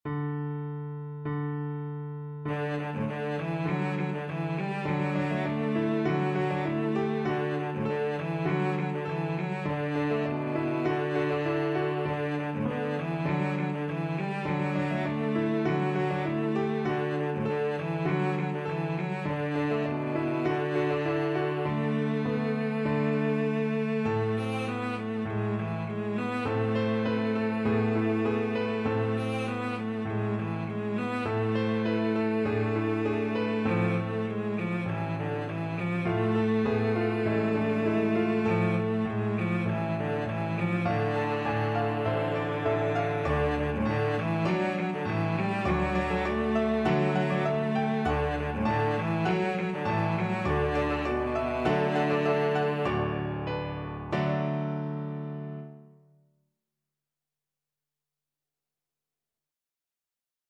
Cello
D major (Sounding Pitch) (View more D major Music for Cello )
4/4 (View more 4/4 Music)
With energy
A3-C5
Traditional (View more Traditional Cello Music)